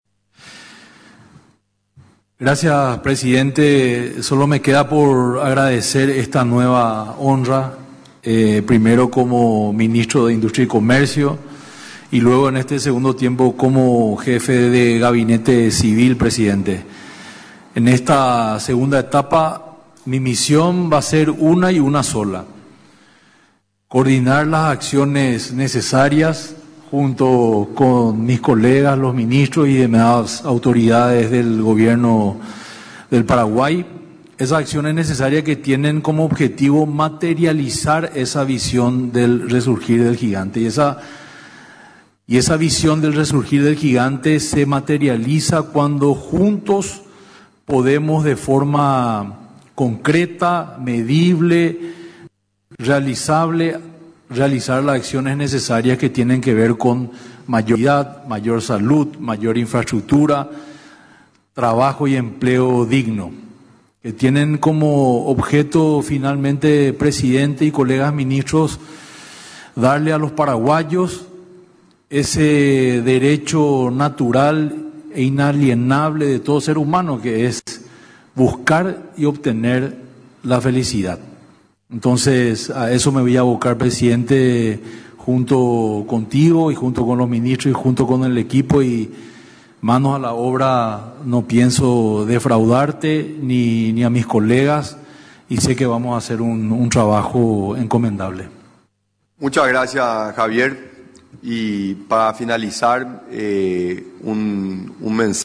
En otra parte de la conferencia de prensa, resaltó que se logró construir un equipo de trabajo con viceministros y directores capaces que lograron entender la filosofía de trabajo al cumplir las metas.